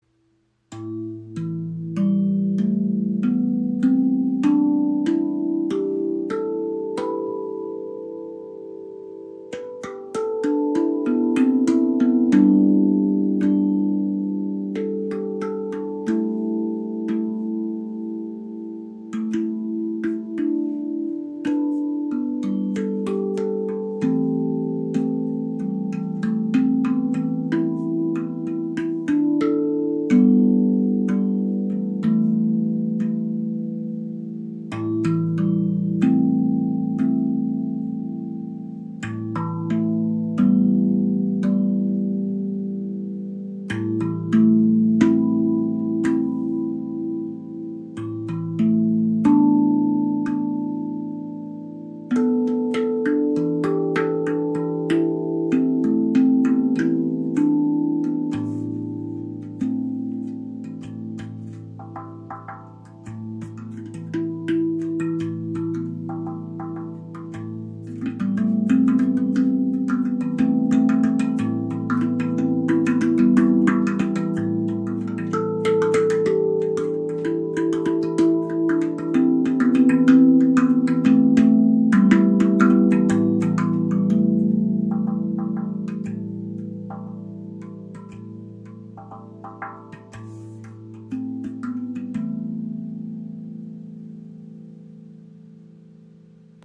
A Amara 11 (Tonefields) A, E, G, A, B, C, D, E, G, A, B
Weite, harmonische Stimmung mit offenem und zugleich zentrierendem Charakter. Sie schafft ein sanft schwingendes Klangfeld voller Ruhe, Klarheit und natürlicher Balance und eignet sich ideal für Meditation, Klangarbeit und therapeutisches Spiel.